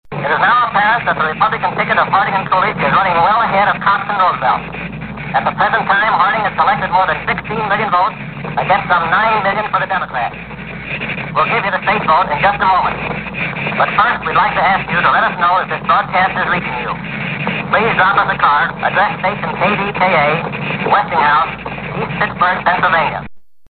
KDKA election results broadcast.
KDKA_radio_election_broadcast.mp3